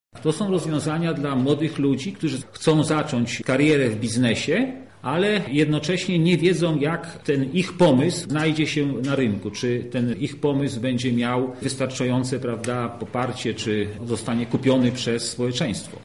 Andrzej Stanisławek -mówi Andrzej Stanisławek, senator RP